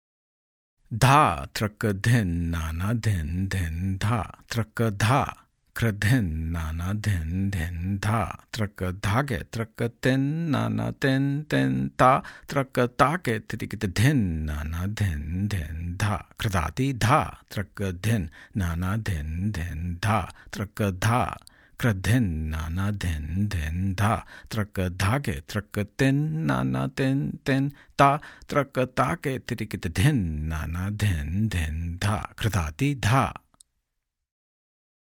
In this section and the next, there are practice variations, accompaniment variations, and theka improvisations in vilambit and madhyalay tempos (slow and medium):
• Accompaniment variations present embellishments and fills which are more typical of accompaniment and are not as repetitive.